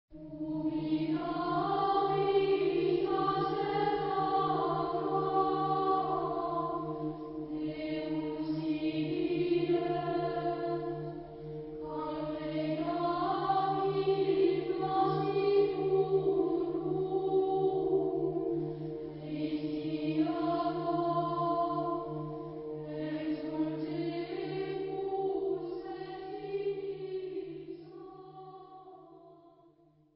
Genre-Style-Forme : Sacré ; Chant grégorien ; Antienne ; Verset
Caractère de la pièce : cantabile ; recueilli ; fervent
Type de choeur : unisson  (1 voix unisson )
Tonalité : mode de fa